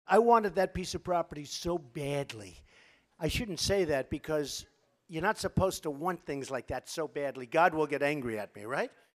Trump took his act on the road to Tennessee, where he thrilled a conservative audience with an off-the-cuff routine that bordered on stand-up comedy.